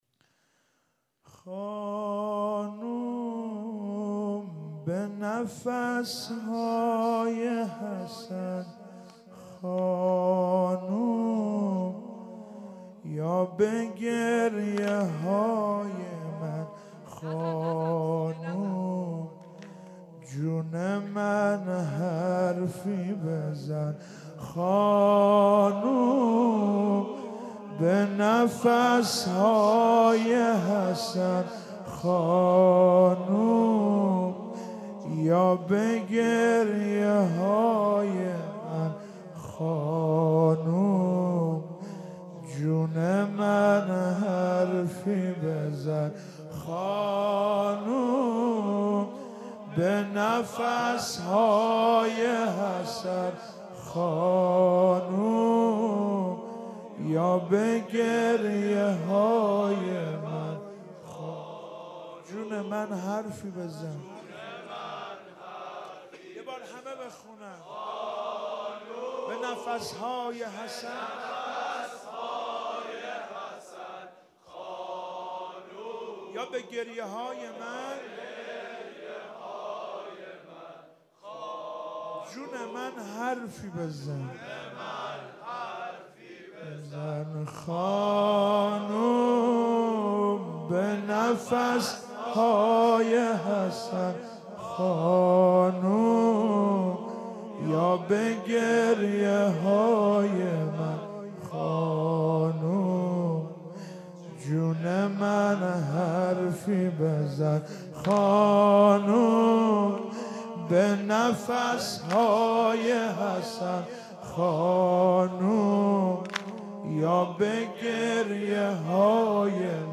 فاطمیه
واحد مداحی